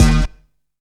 FLUFFY STAB.wav